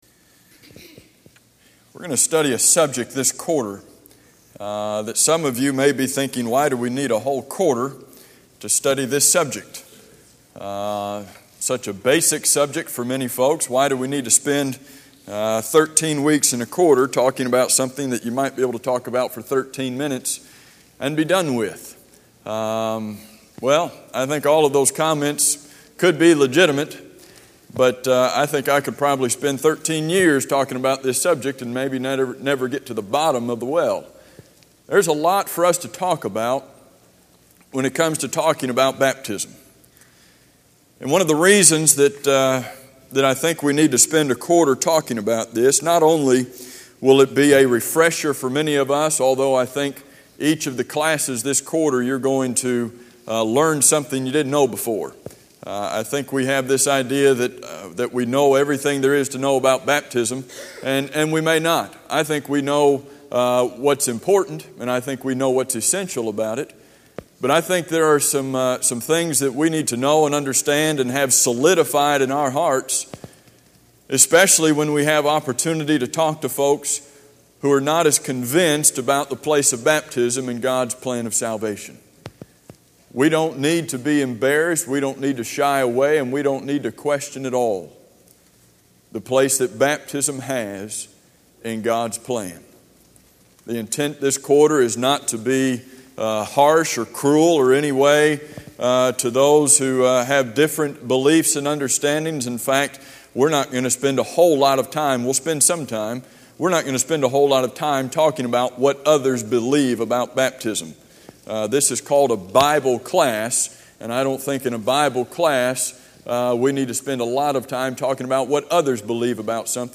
Sermon Audio
L01-Baptism-Introduction.mp3